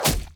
Free Fantasy SFX Pack
Spell Impact 3.wav